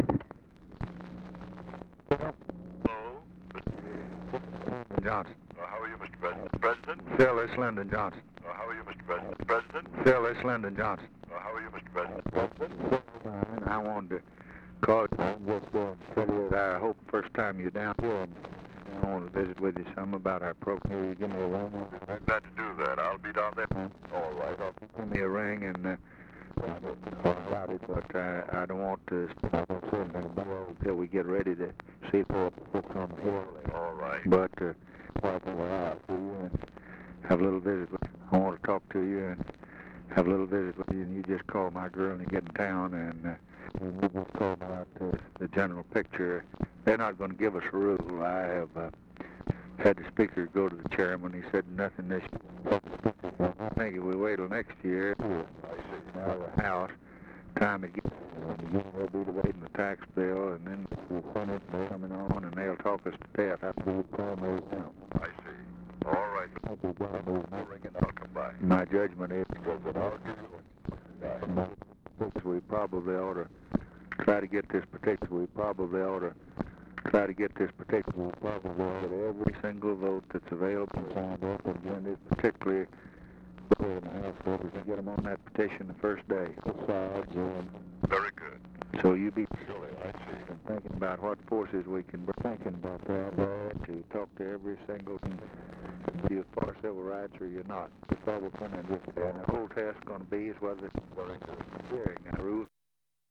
Conversation with A. PHILIP RANDOLPH, November 29, 1963
Secret White House Tapes